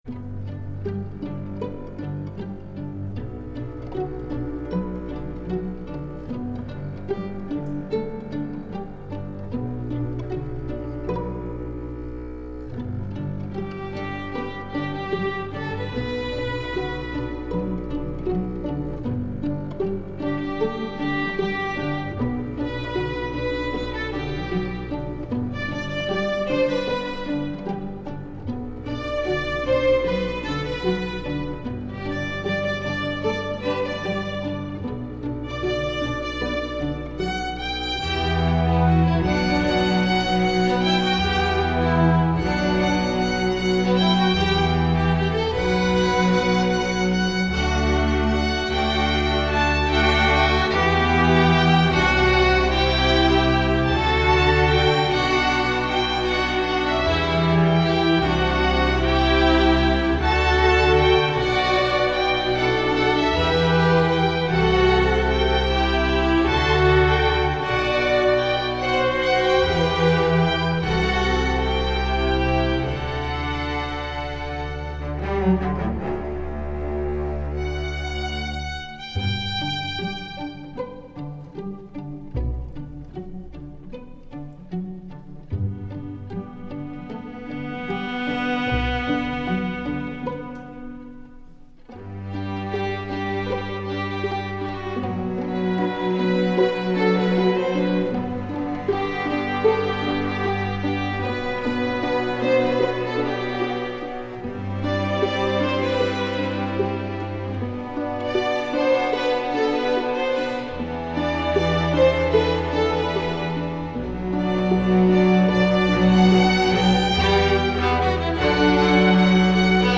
Instrumentation: string orchestra
pop, rock, children, love, metal, instructional, choral